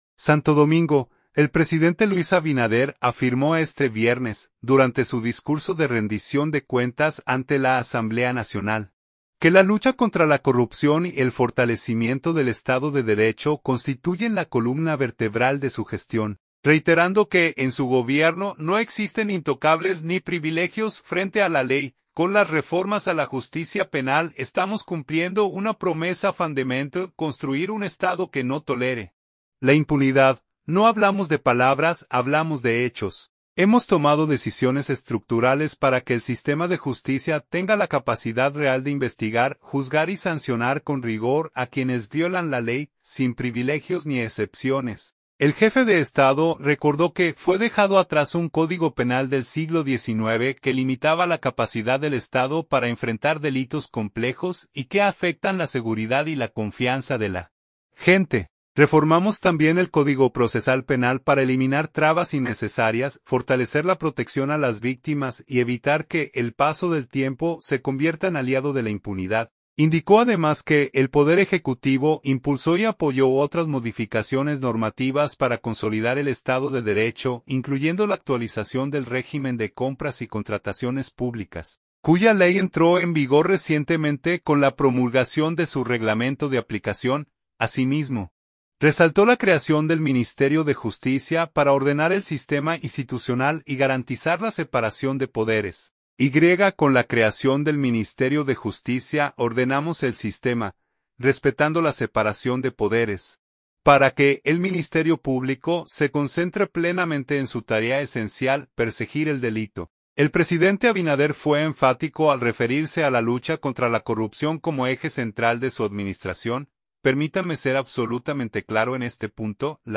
Santo Domingo. – El presidente Luis Abinader afirmó este viernes, durante su discurso de Rendición de Cuentas ante la Asamblea Nacional, que la lucha contra la corrupción y el fortalecimiento del Estado de Derecho constituyen la columna vertebral de su gestión, reiterando que en su gobierno no existen intocables ni privilegios frente a la ley.